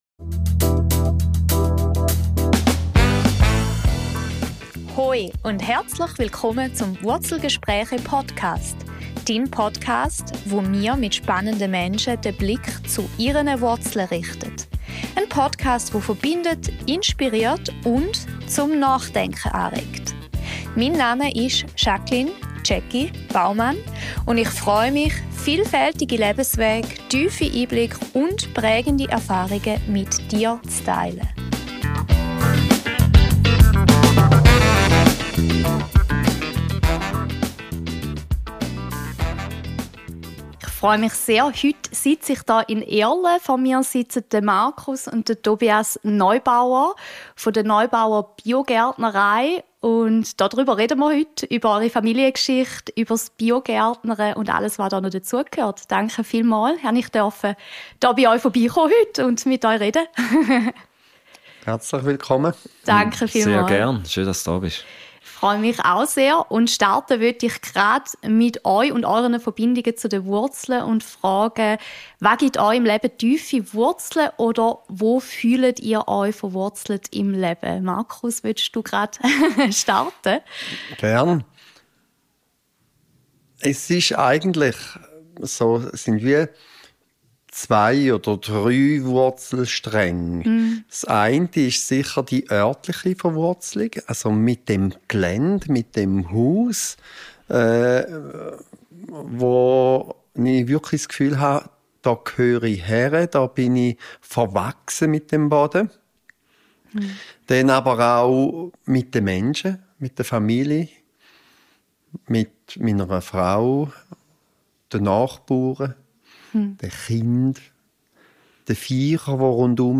Ein Gespräch, das Mut macht, den eigenen Weg zu gehen, verwurzelt zu bleiben und dem Leben mit Vertrauen zu begegnen.